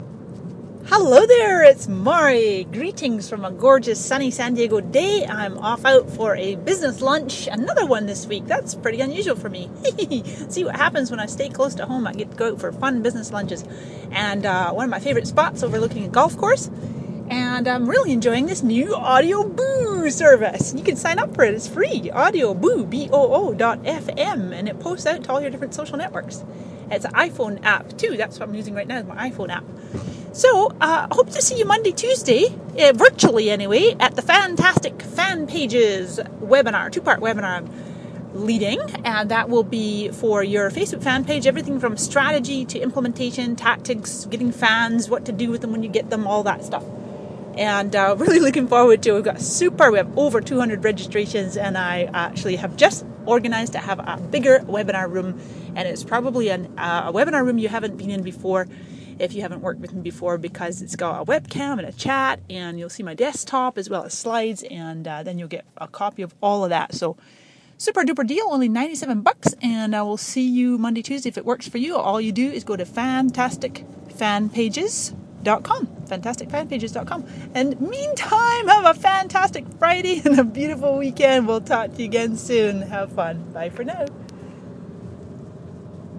A wee voice message from me to you!!
77802-a-wee-voice-message-from-me-to-you.mp3